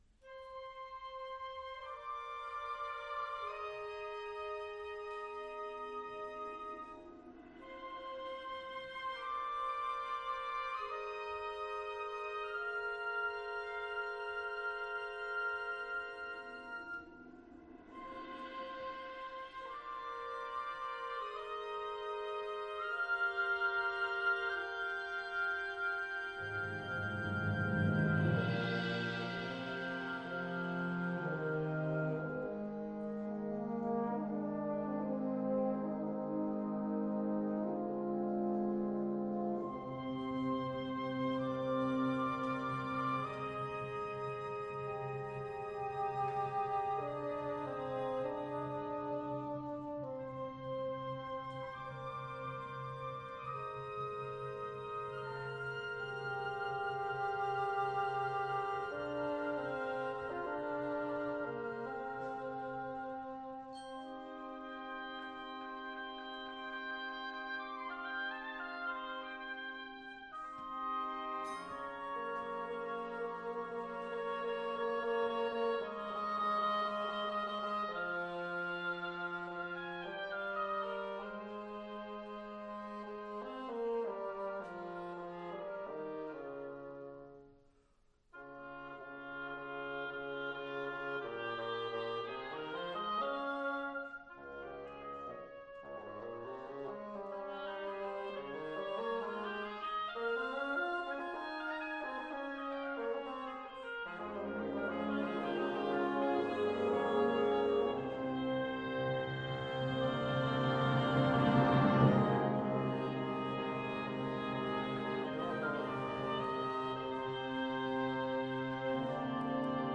Solo Oboe
Solo Bassoon
Percussion I: Glockenspiel, Gong, Anvil, Shaker
Percussion III: Triangle, Snare Drum, Djembe
This work is in one movement with several sections: